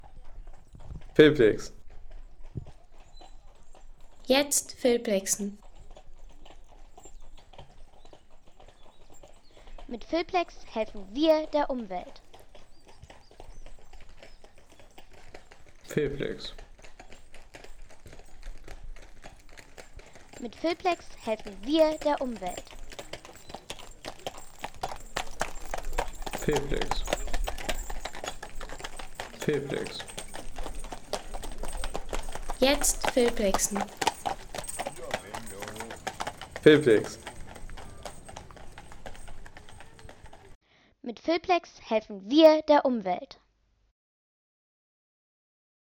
Pferde / Pferdekutsche Trab